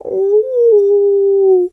pgs/Assets/Audio/Animal_Impersonations/wolf_2_howl_soft_01.wav at master
wolf_2_howl_soft_01.wav